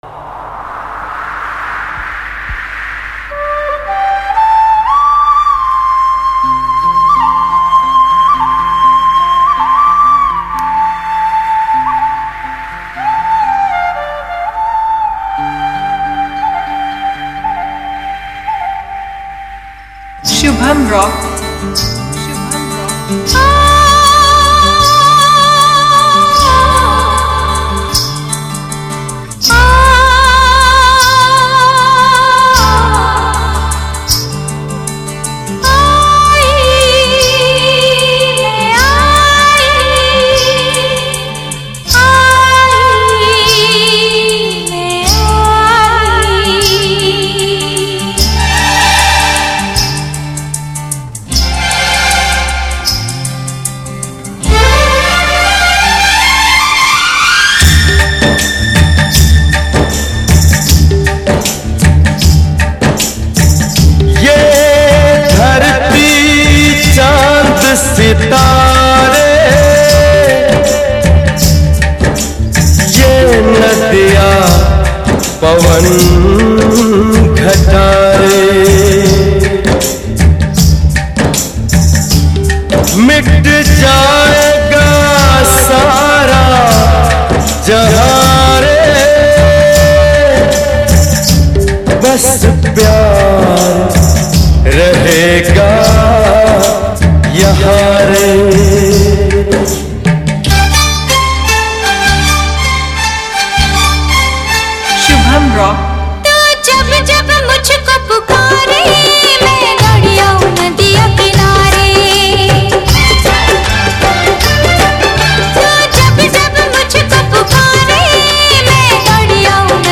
Mela Competition Filters Song